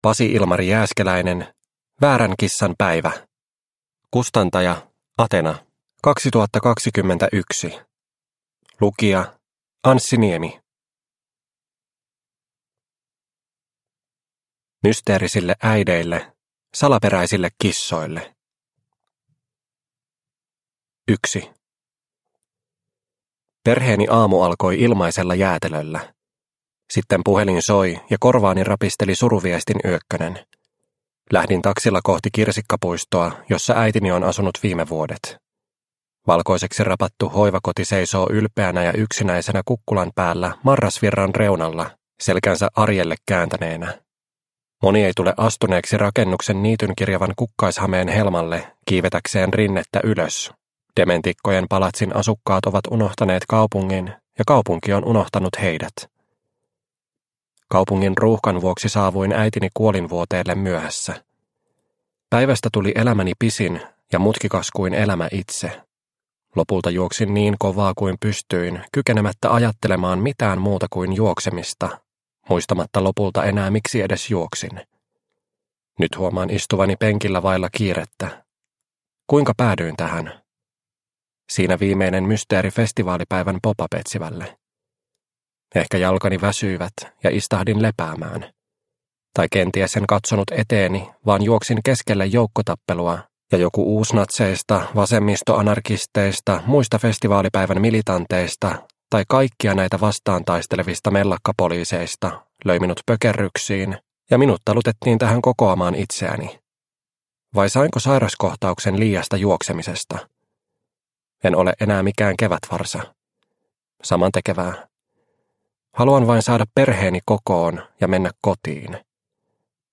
Väärän kissan päivä – Ljudbok – Laddas ner